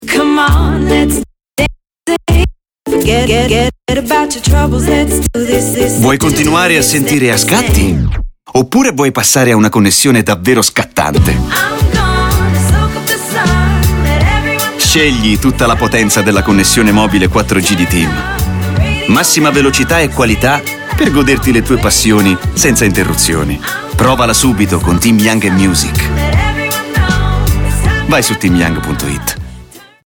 Le vivaci e gioiose note